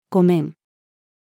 ごめん-female.mp3